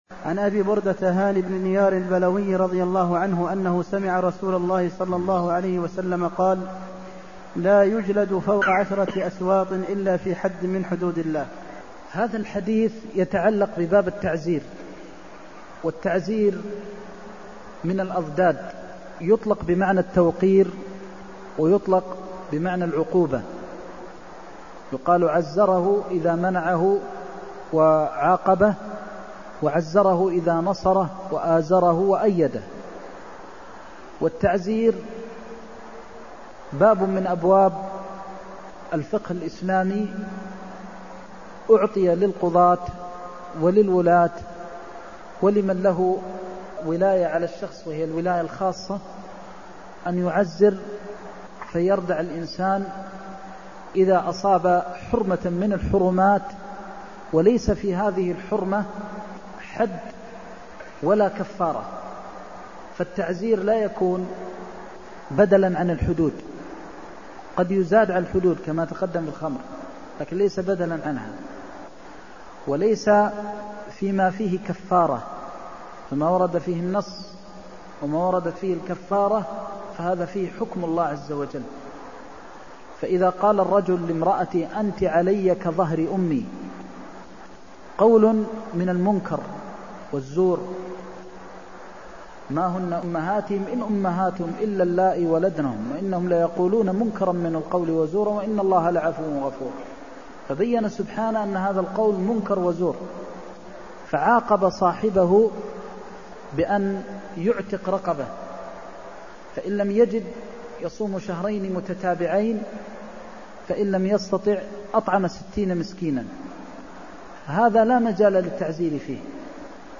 المكان: المسجد النبوي الشيخ: فضيلة الشيخ د. محمد بن محمد المختار فضيلة الشيخ د. محمد بن محمد المختار لا يجلد فوق عشرة أسواط إلا في حد من حدود الله (339)